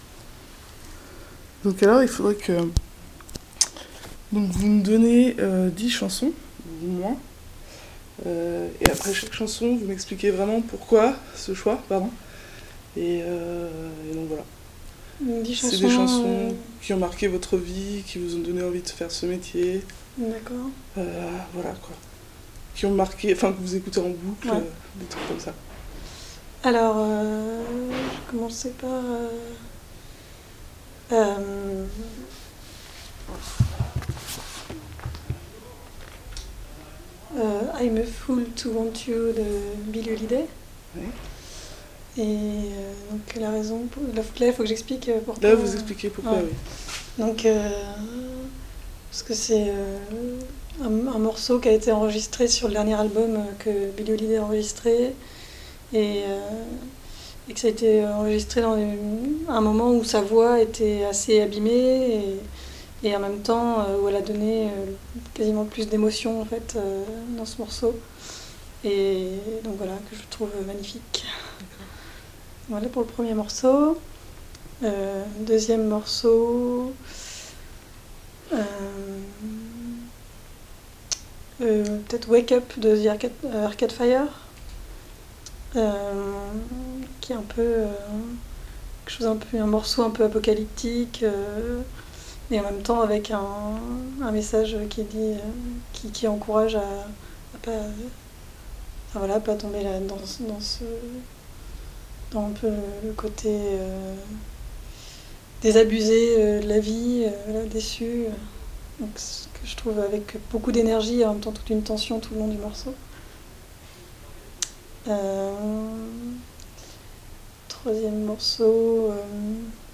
Folk, playlist, Pop